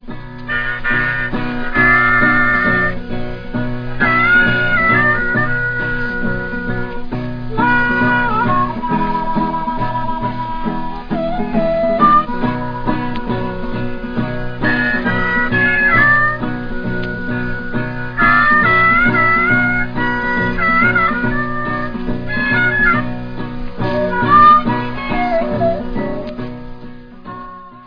вокал, гитара
губная гармоника